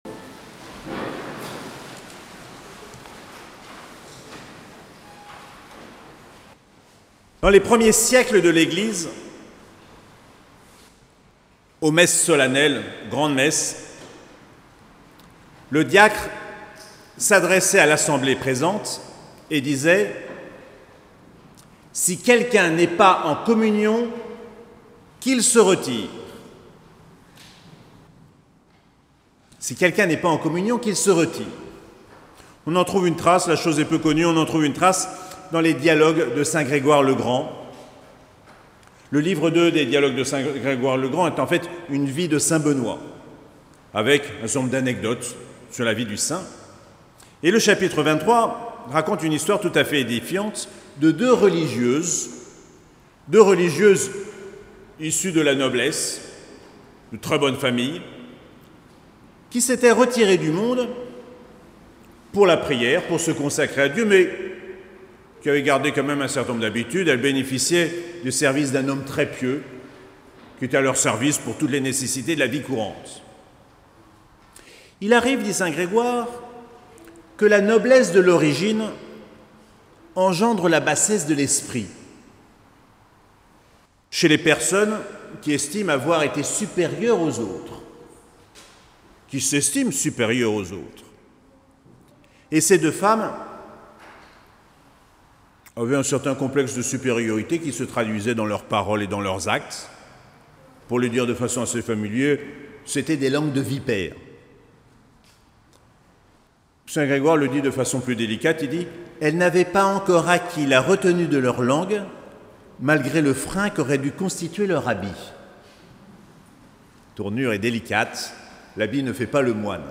21ème Dimanche du Temps Ordinaire - 22 août 2021
Précédentes homélies